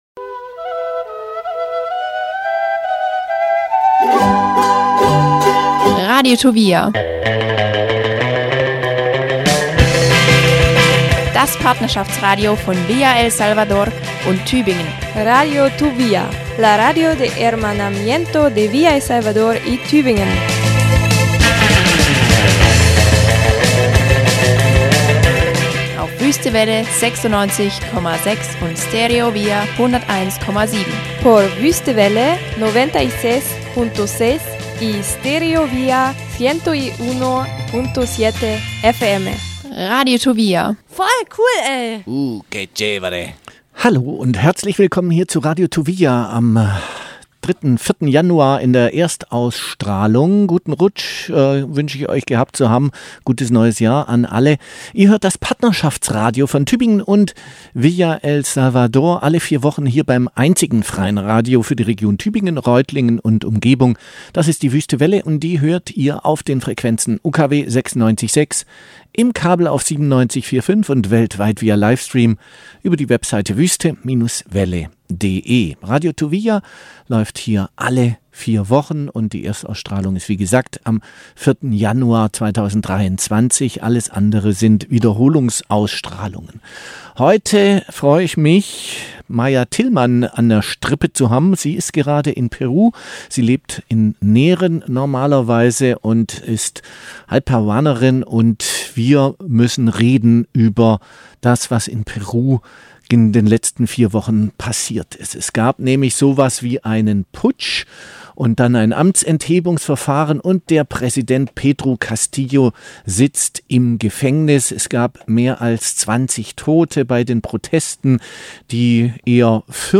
Das Interview führten wir am 4. Januar, dem letzten Tag der Protestpause über die Feiertage. Im Anschluss flammten die Proteste und besonders die gewaltvolle Repression wieder auf mit mehr als 20 Todesopfern und vielen Verletzten.